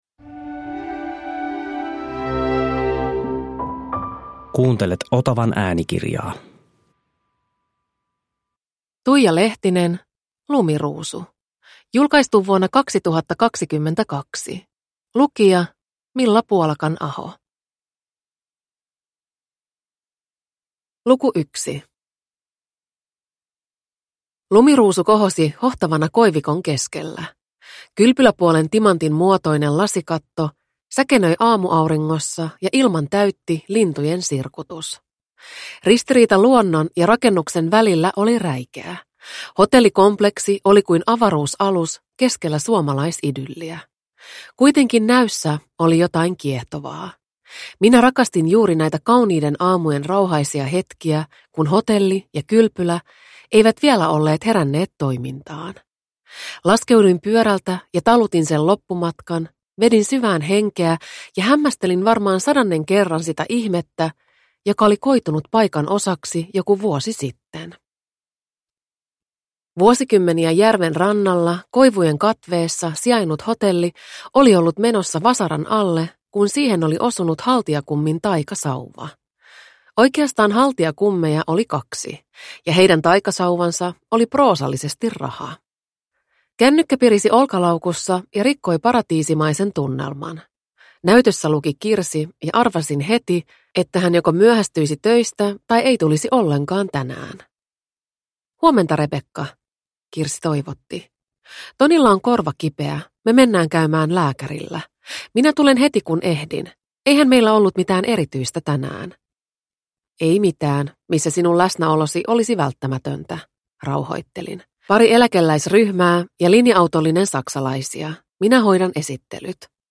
Lumiruusu – Ljudbok – Laddas ner